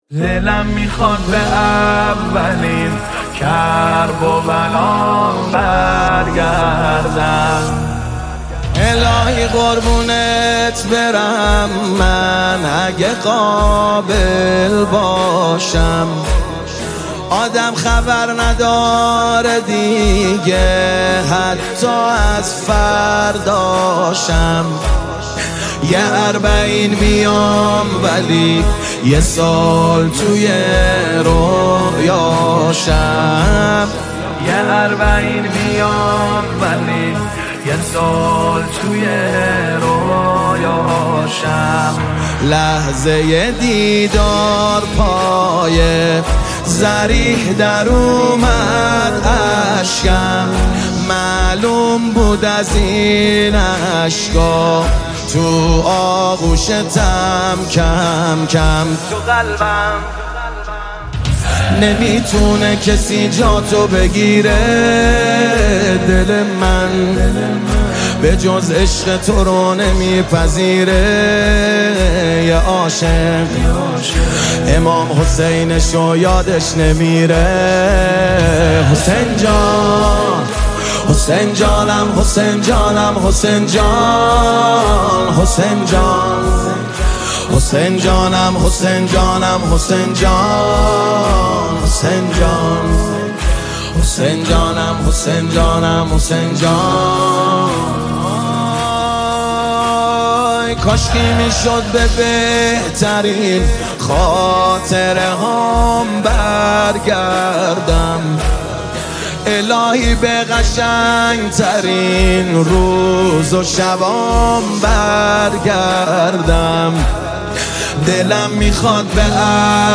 نماهنگ دلنشین
مداحی اربعین